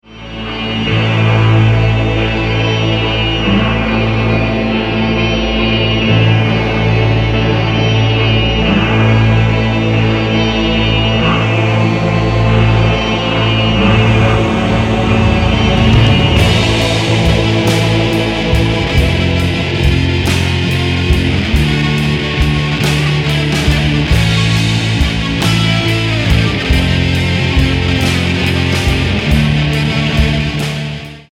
bass guitar, keys, noise pulses
sitars, lead guitar